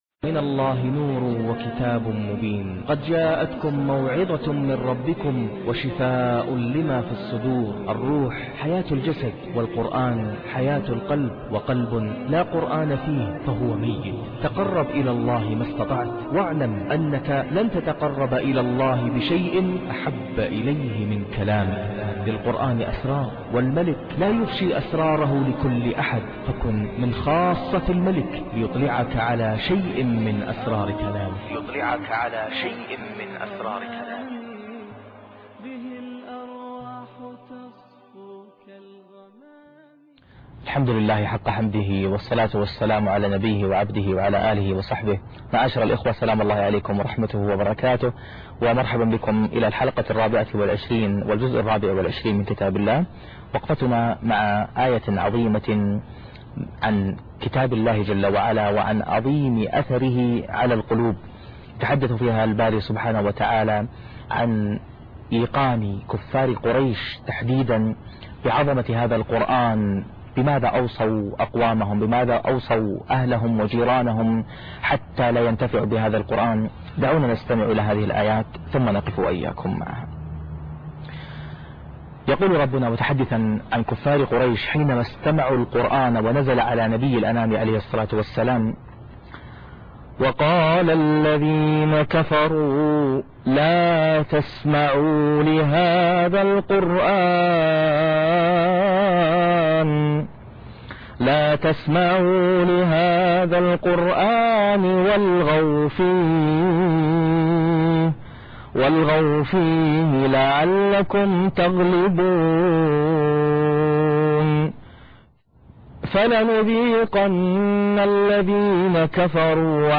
وقفة مع آية تتحدث عن عظمة القرآن( 3/8/2013) ثنائيات قرآنية - القاريء ناصر القطامي